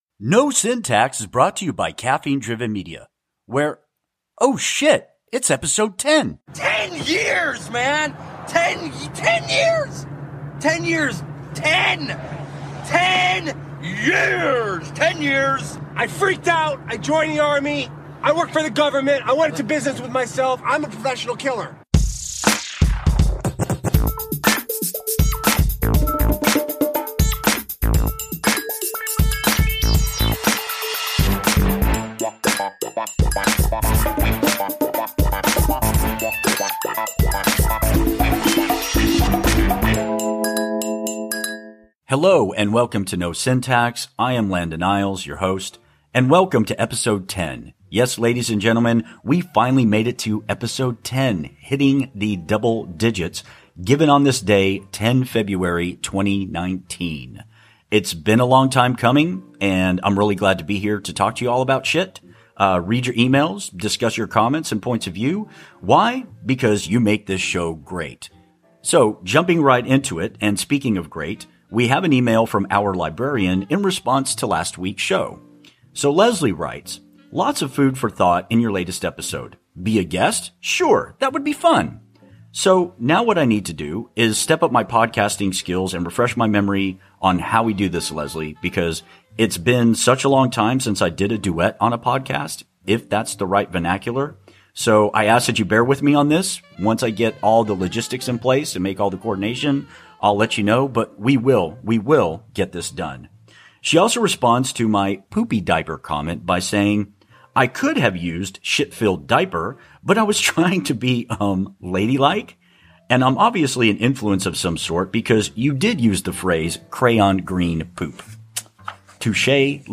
Today on NO SYNTAX we respond to a long, listener email, and introduce our new “Media Beans” segment on Iron Bean Coffee Company! We were a bit amped up on their “Fear No Evil” coffee, and it shows!